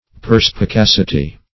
Perspicacity \Per`spi*cac"i*ty\
(p[~e]r`sp[i^]*k[a^]s"[i^]*t[y^]), n. [L. perspicacitas: cf.